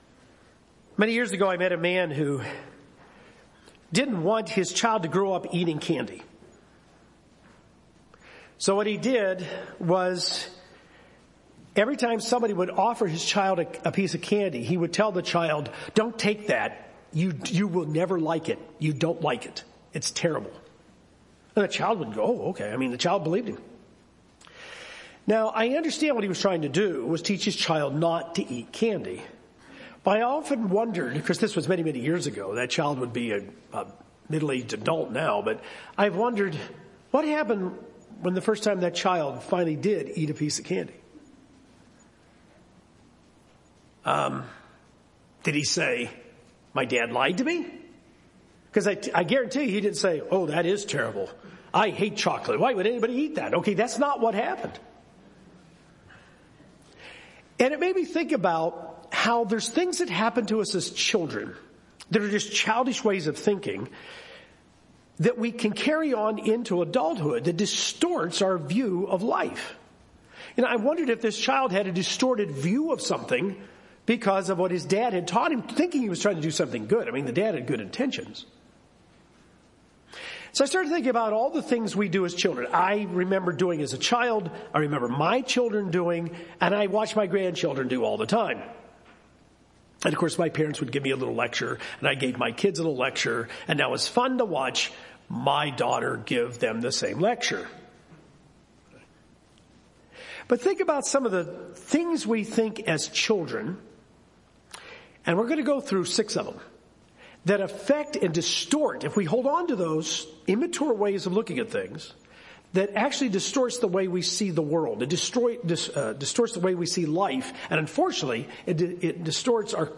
Things that happen to us when we are children make us think in childish ways, and often stick with us as we grow into adults. In this sermon, here are six childish ways of thinking and how to overcome them to become more spiritually mature adults.